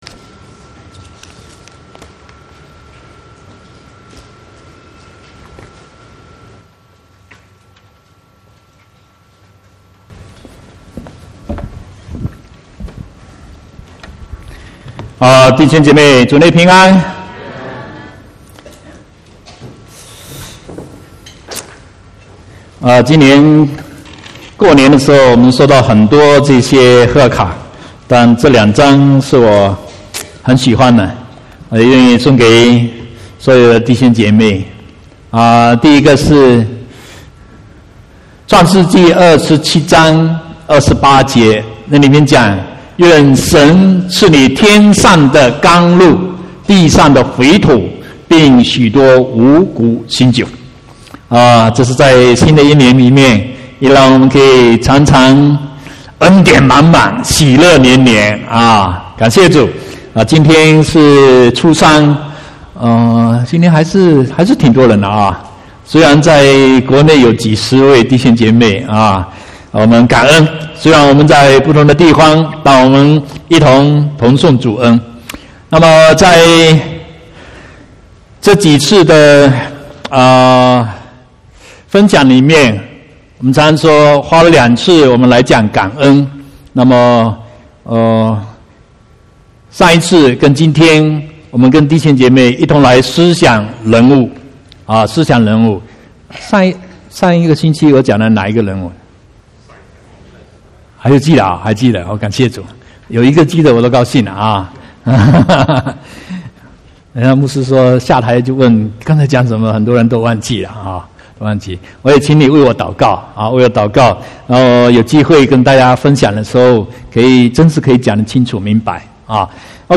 18/2/2018 國語堂講道